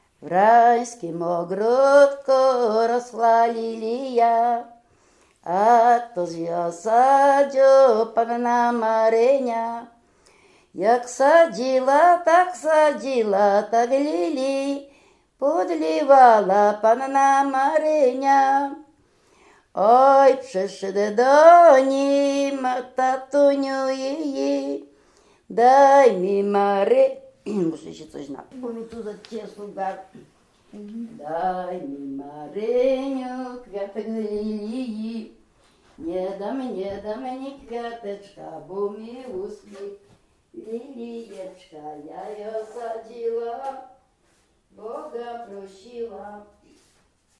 województwo dolnośląskie, powiat lwówecki, gmina Mirsk, wieś Mroczkowice
W wymowie Ł wymawiane jako przedniojęzykowo-zębowe;
e (é) w końcu wyrazu zachowało jego dawną realizację jako i(y)
Kolęda życząca